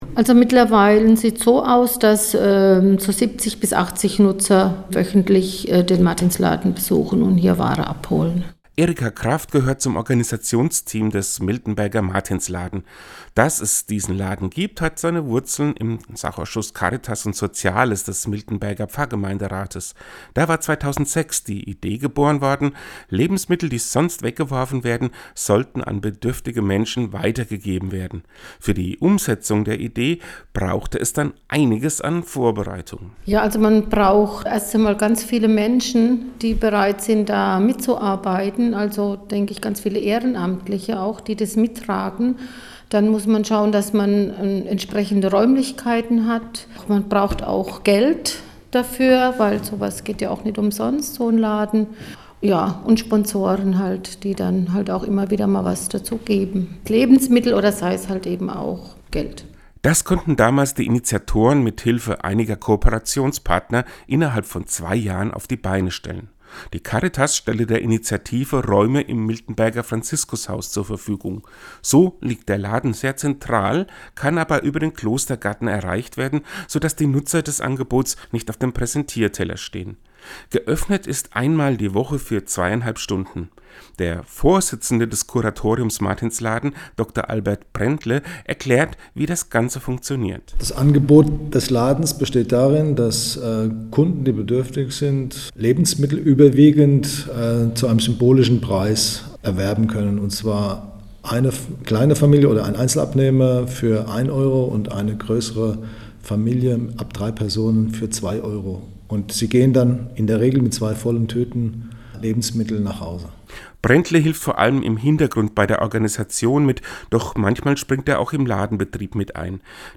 Den Radiobericht finden Sie unten zum Download!